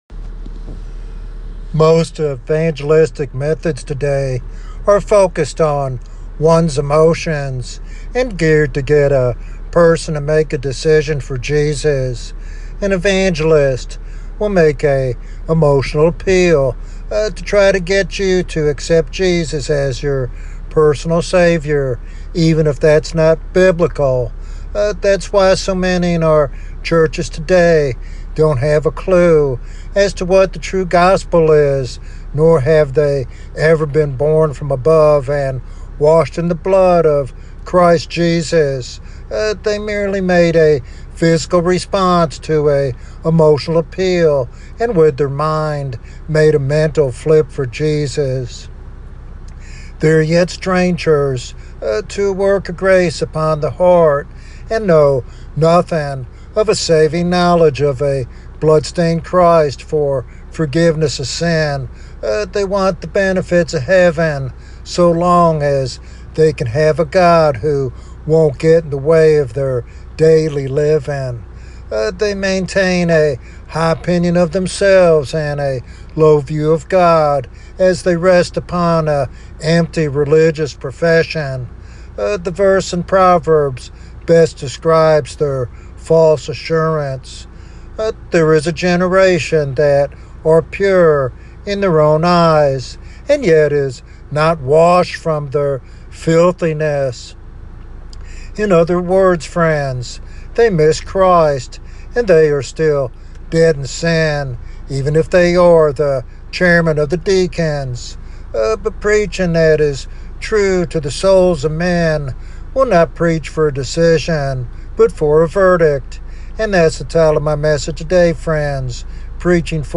This sermon highlights the necessity of understanding salvation as more than a decision, but as a solemn verdict before God.